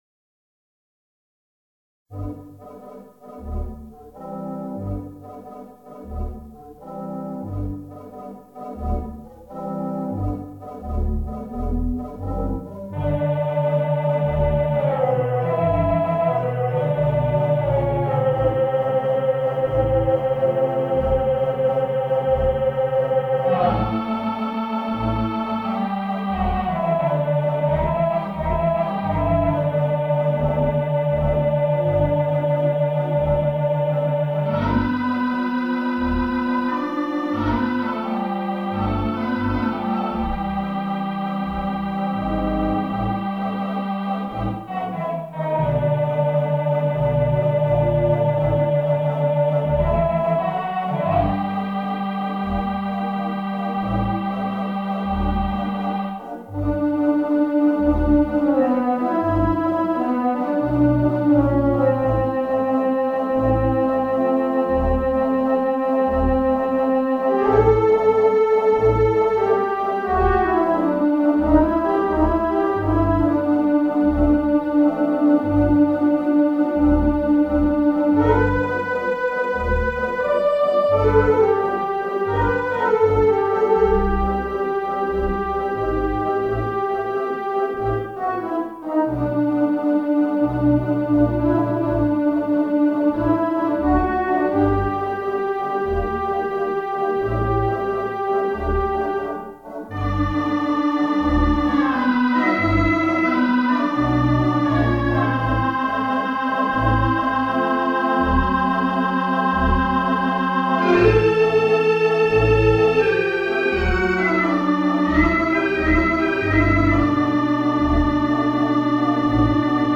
Virtual Theatre Pipe Organ